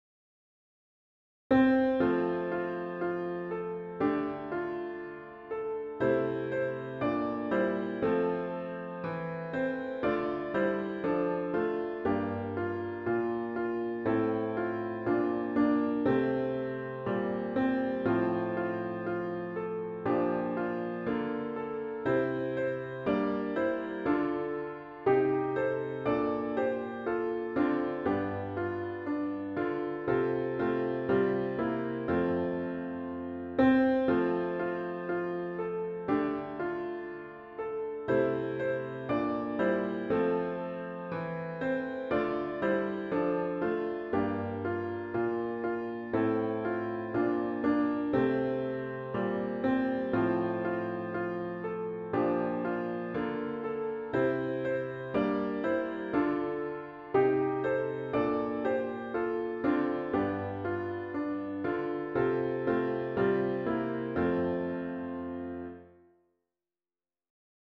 CLOSING HYMN   “Wherever I May Wander